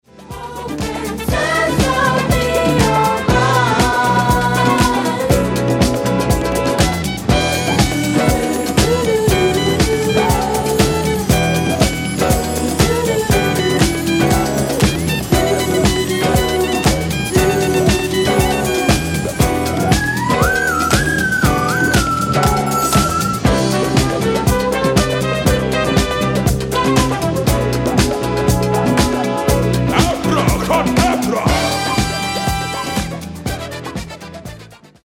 Genere:   Disco | Funky | Soul |